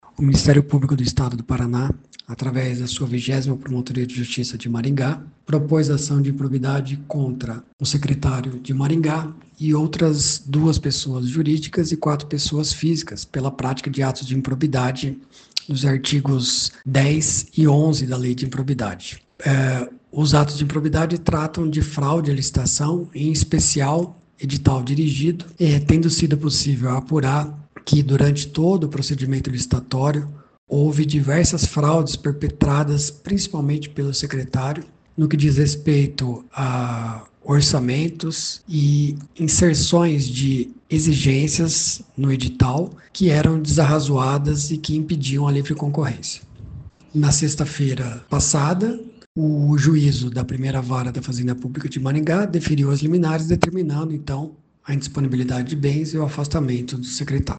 Ouça o que diz o promotor de Justiça Leonardo Vilhena: